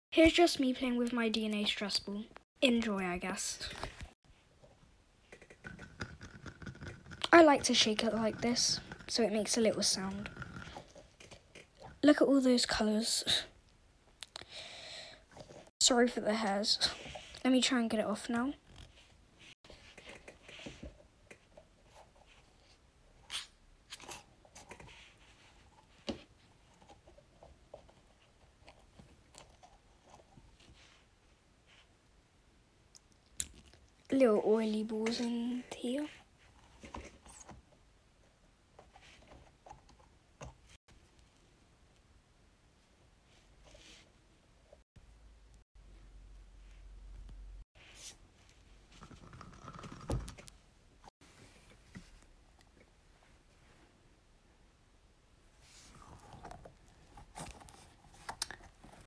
just me playing with my dna 🧬 stress ball ig lol